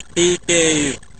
Tech. description: (44.1k, 16bit, mono)